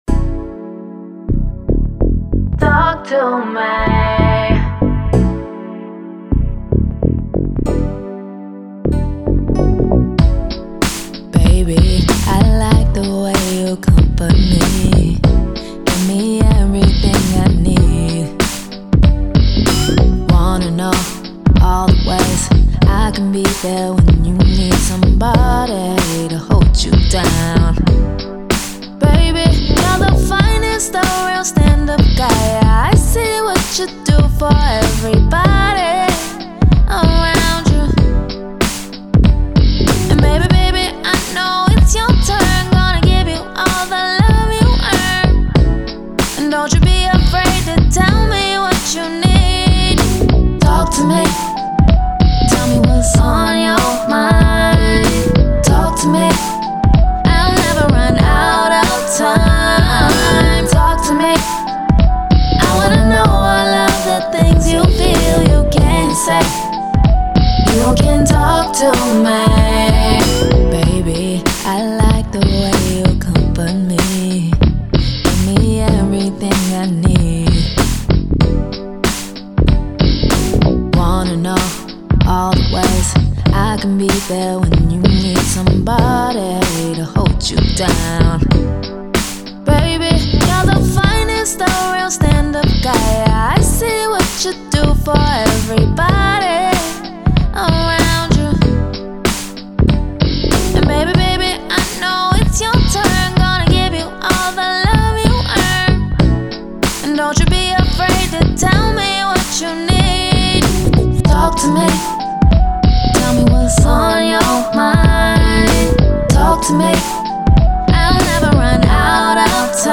90s, R&B
B Minor